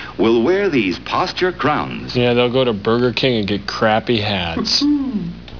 BKHats.wav - MST3K Short clip on Posture. Keep in mind, the hats did look like BK Birthday hats..